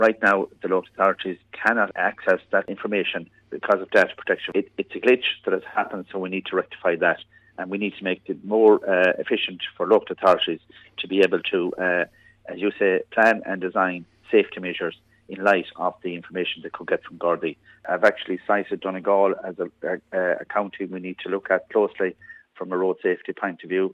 Junior Minister Sean Canney has responsibility for road safety. He told Highland Radio News that Donegal is one of the counties the government will be looking at closely……..